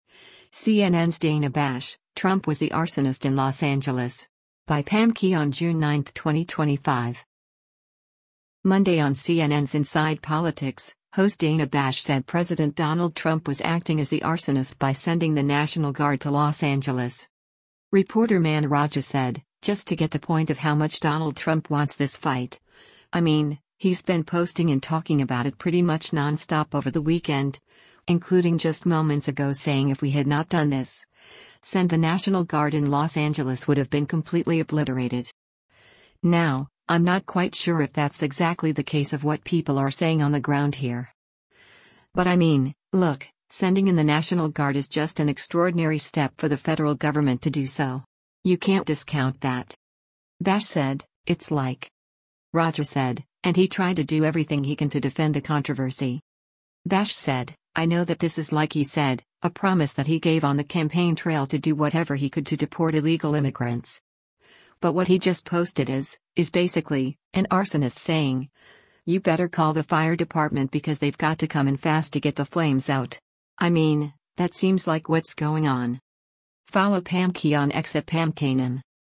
Monday on CNN’s “Inside Politics,” host Dana Bash said President Donald Trump was acting as the “arsonist” by sending the National Guard to Los Angeles.